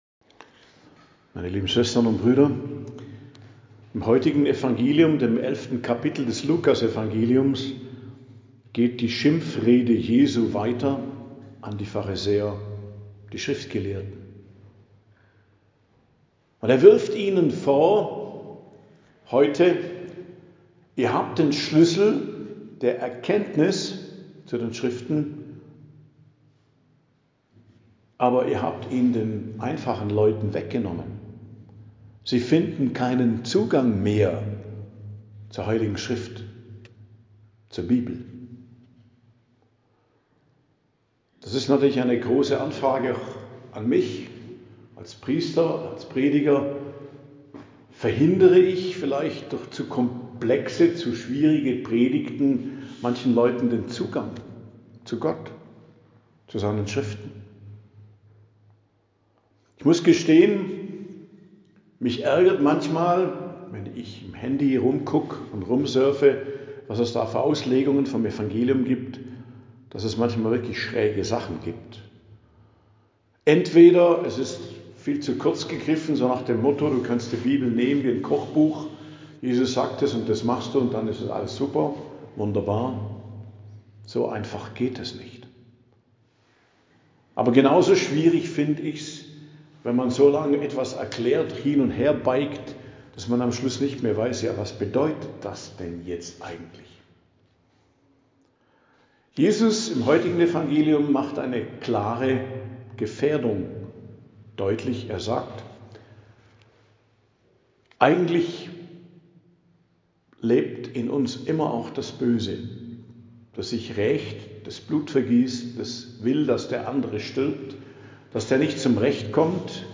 Predigt am Donnerstag der 28. Woche i.J., 16.10.2025